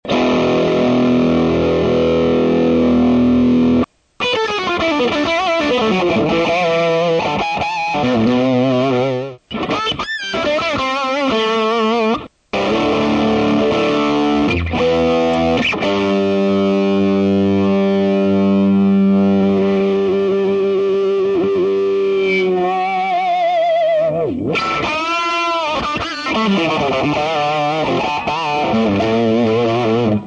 Crunch using the Ibanez bridge humbucker Vol 8 Bass 5 Middle 9 Treble 7 Master 8 Dimed using the Ibanez bridge humbucker Vol 12 Bass 5 Middle 9 Treble 7 master 12 "Fat" switch on Clean using the Telecaster Vol 2 Bass 5 Middle 9 Treble 7 Master 12